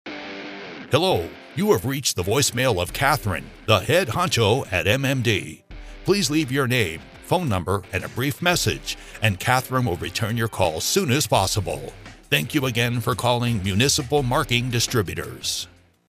Our studio produces custom auto-attendant greetings for any style business and include royalty free music, sound effects if desired, and voice over.
Voicemail Greeting 1